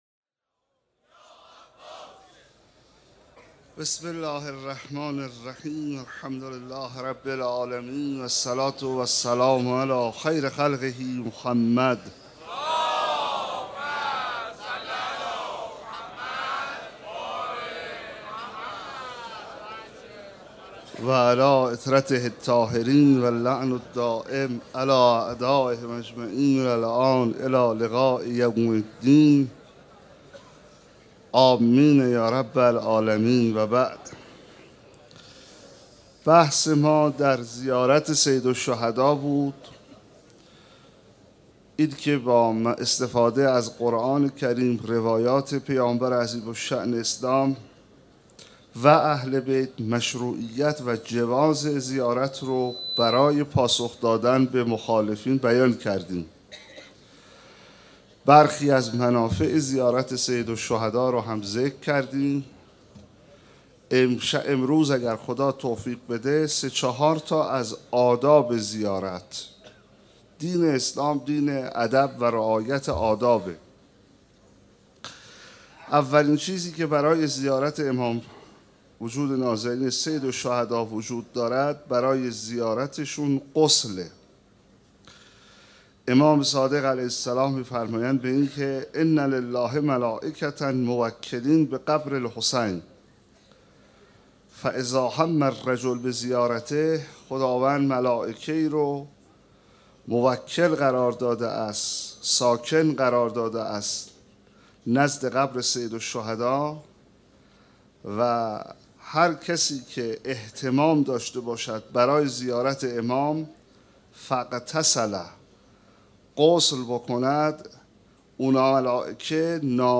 شب عاشورا محرم 1436 - هیات رایه العباس B > سخنرانی